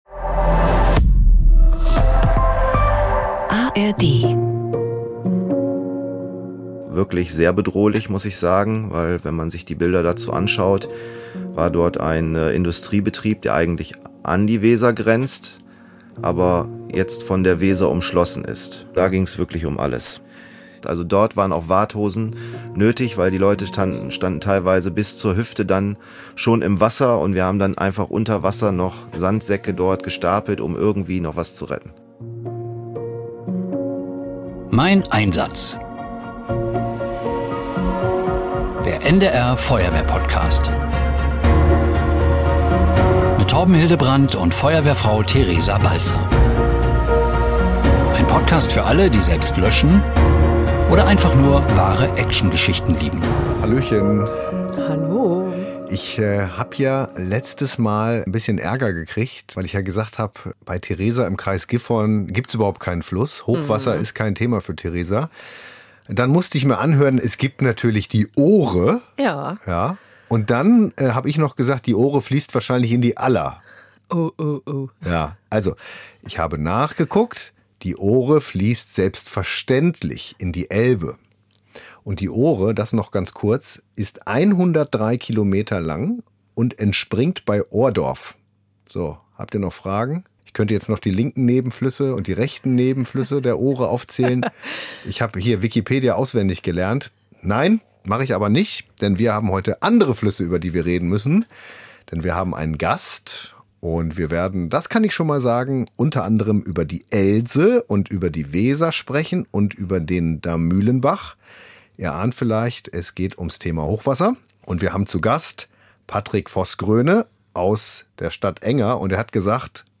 Emotionen pur und spannende Geschichten.